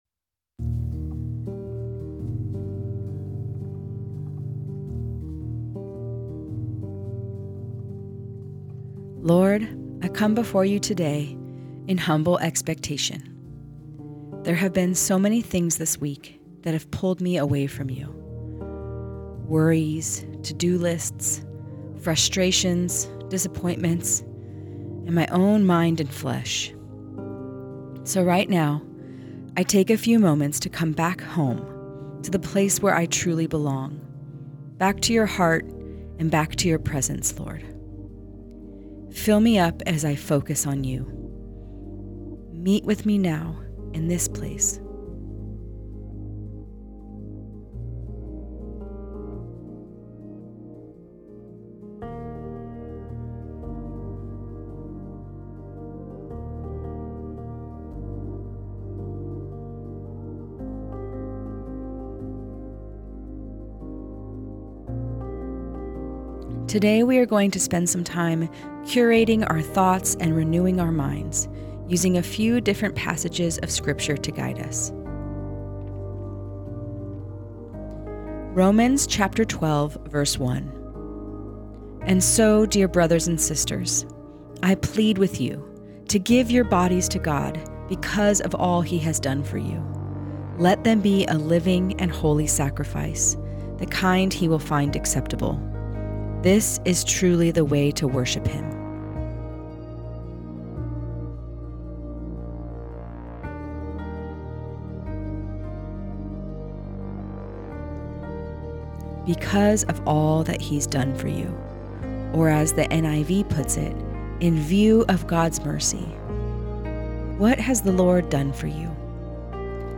Guided Listening Practice Prepare Lord, I come before you today in humble expectation.